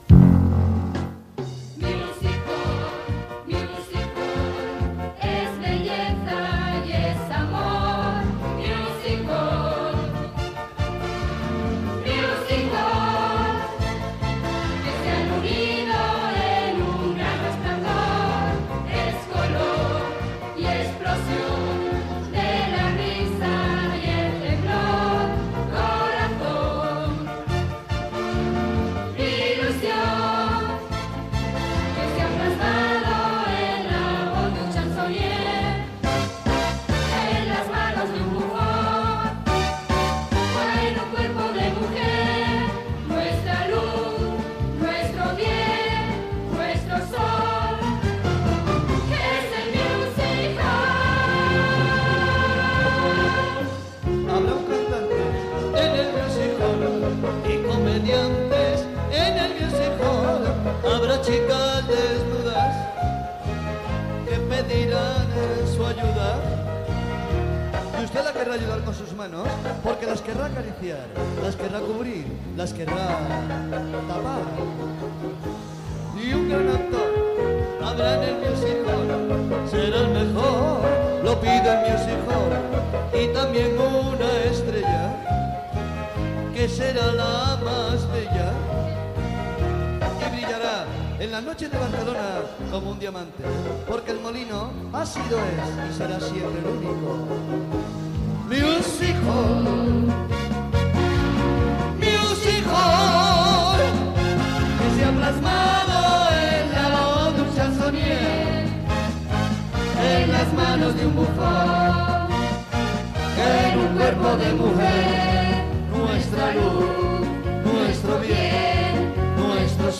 710636c388cced74cc19ce8d2d4516a3f4b414c5.mp3 Títol Ràdio 4 Emissora Ràdio 4 Cadena RNE Titularitat Pública estatal Descripció Transmissió de l'últim espectacle representat al teatre El Molino de Barcelona, abans del tancament temporal: " Historias de El Molino" (10 años de music-hall). Cançó inicial, acudits i comentaris
Entreteniment